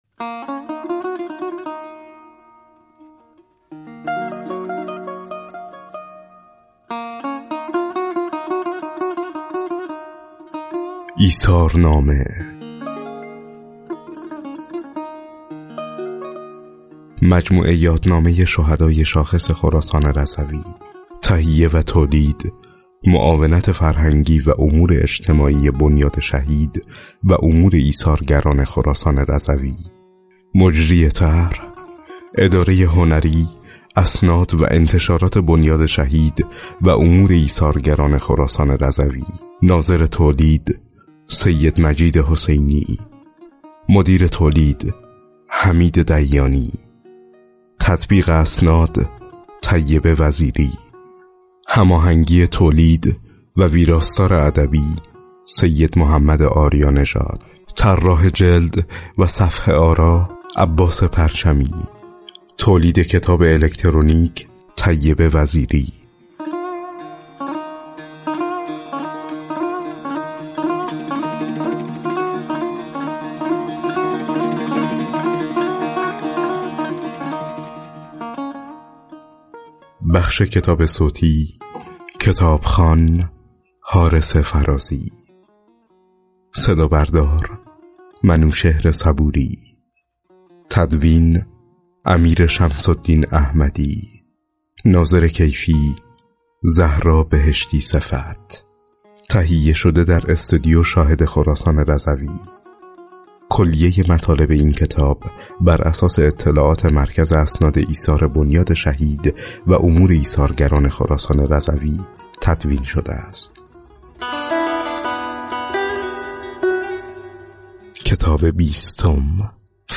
لینک دانلود کتاب صوتی لینک دانلود پوسترها مرورگر شما قابلیت نمایش کتاب الکترونیکی را پشتیبانی نمی کند.